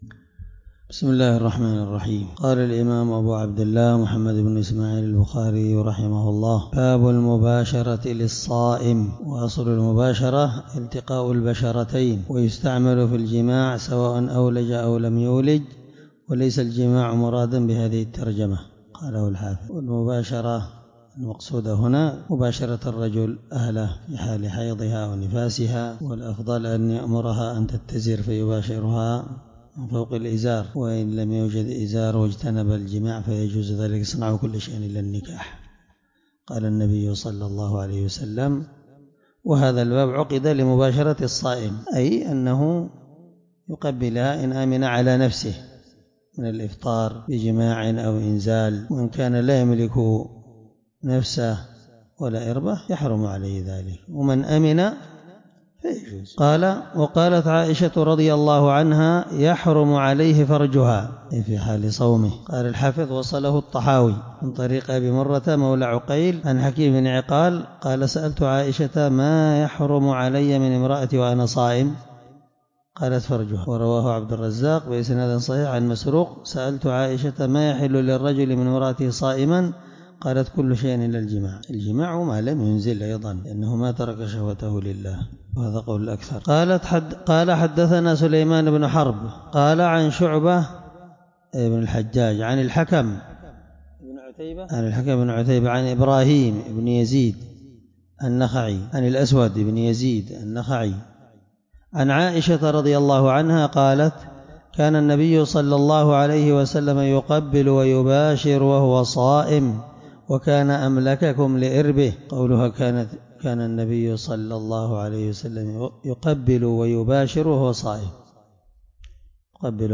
الدرس 23من شرح كتاب الصوم حديث رقم(1927)من صحيح البخاري